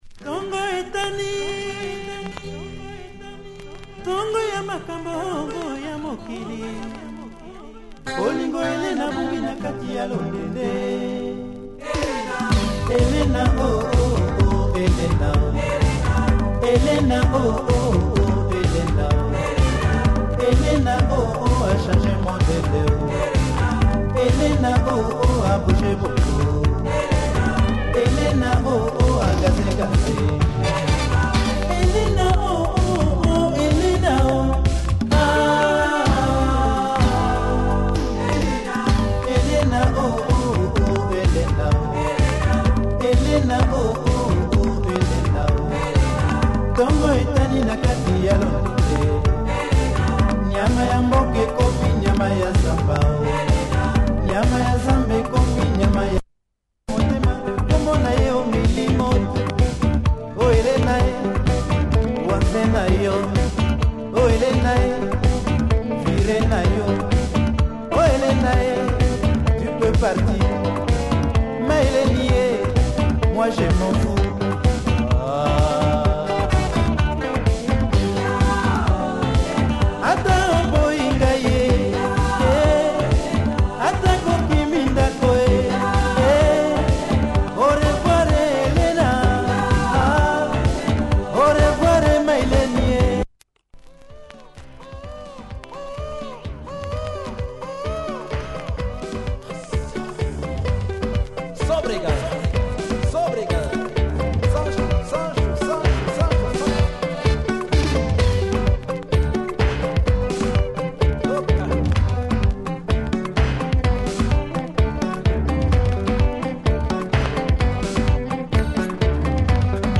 Modern congolese orchestra